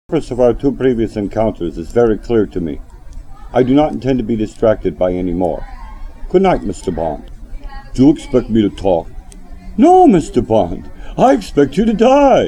(THERE IS A FULL LIST OVER 70 IMPRESSIONS AT THE BOTTOM OF THE PAGE)
My ability to jump between to voice is at a all time low.
My possible 1000 voices.